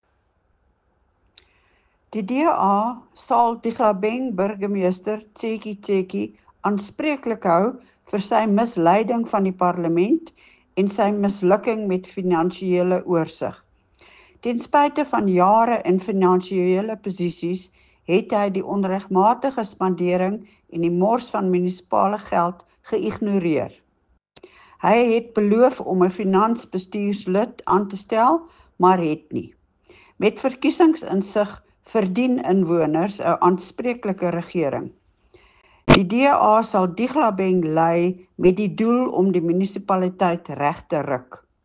Afrikaans soundbite by Cllr Leona Kleynhans.